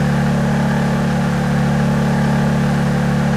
throttle_low.wav